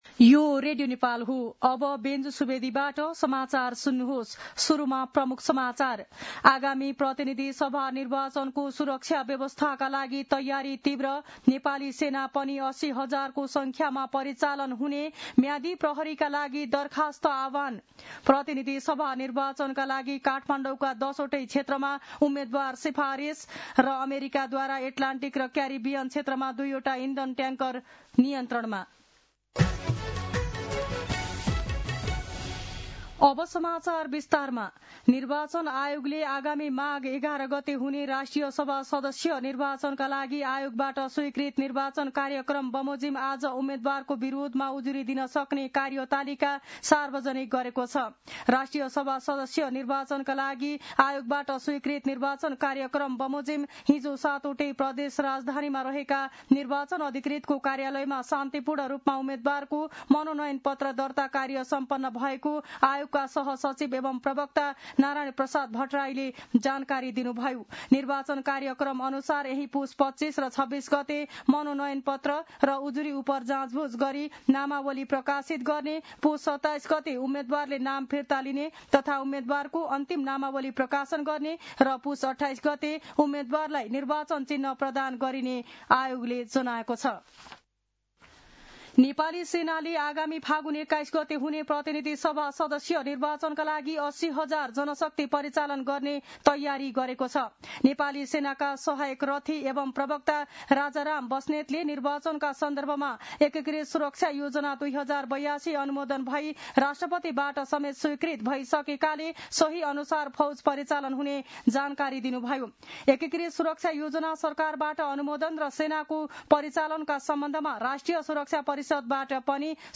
दिउँसो ३ बजेको नेपाली समाचार : २४ पुष , २०८२